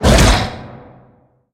Sfx_creature_bruteshark_chase_os_07.ogg